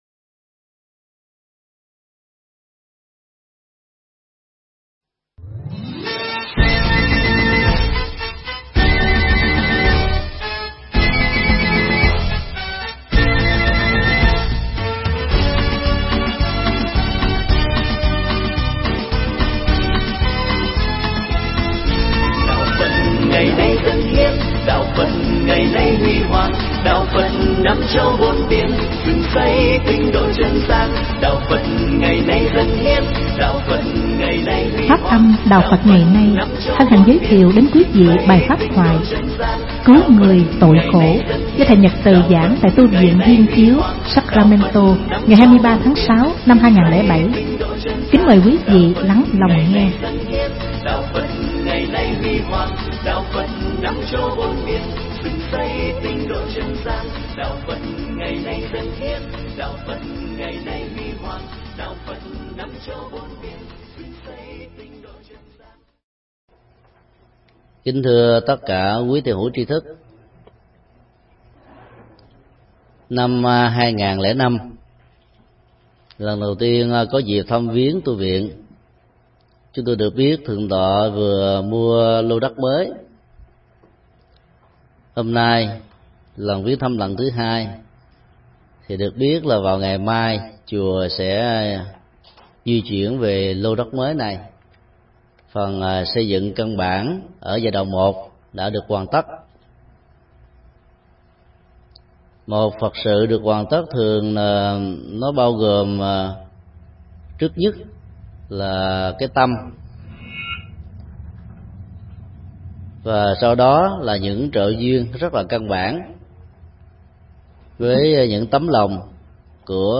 Tải mp3 Thuyết Pháp Cứu người tội khổ
giảng tại Tu Viện Viên Chiếu Sacramanto